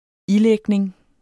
Udtale [ ˈiˌlεgneŋ ]